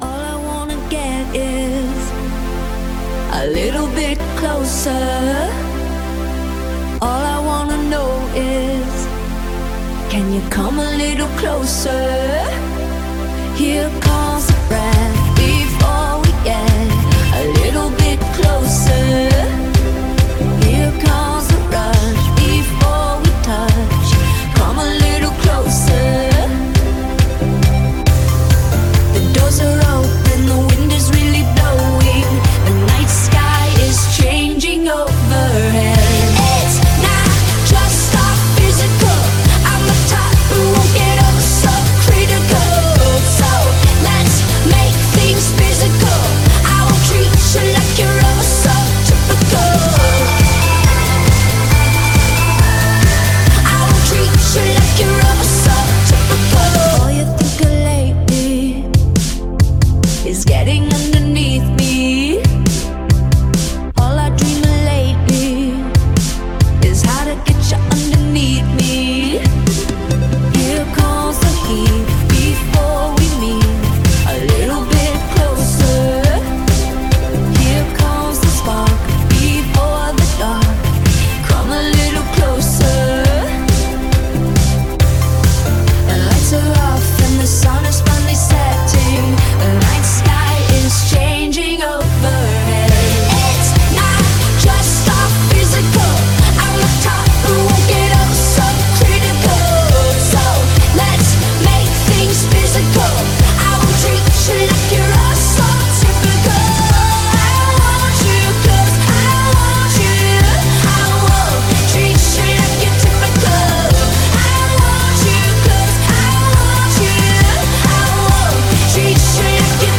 BPM137
Audio QualityMusic Cut